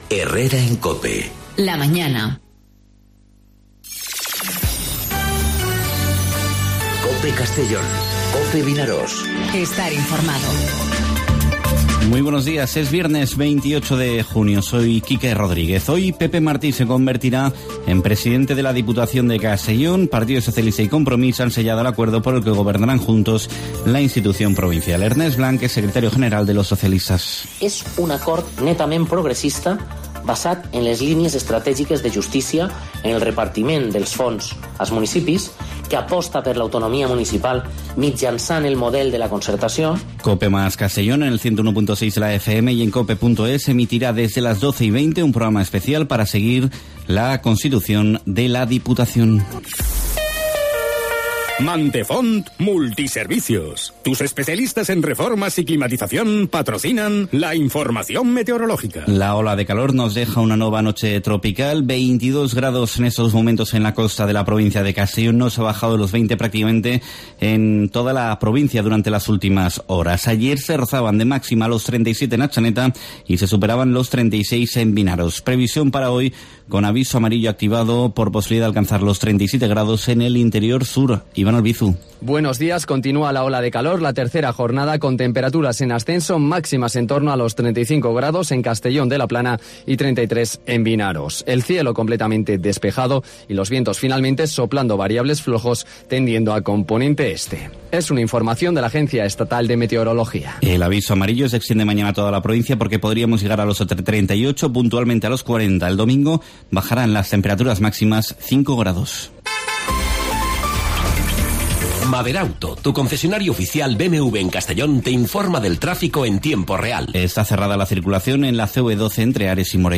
Informativo 'Herrera en COPE' Castellón (28/06/2019)